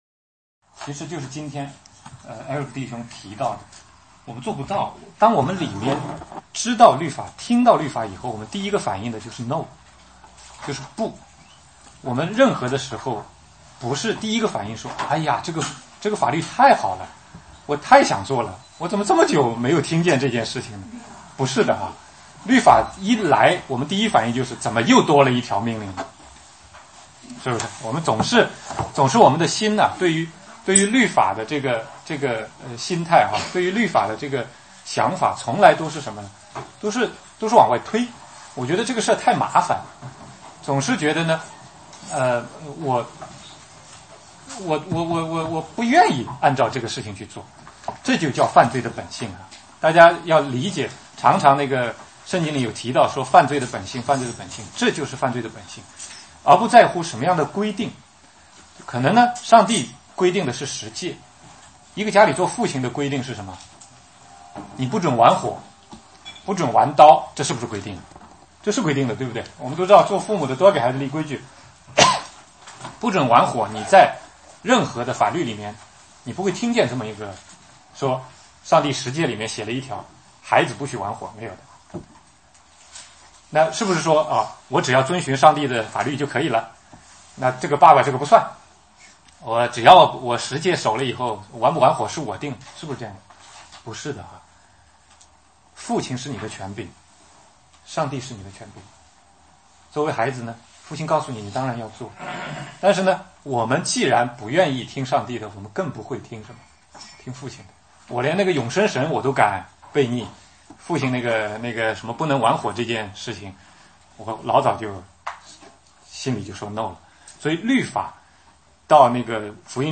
16街讲道录音 - 福音基础